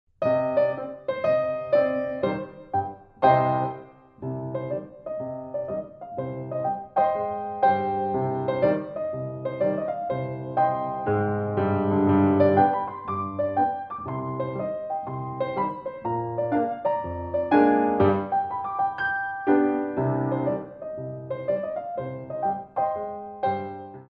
Ragtime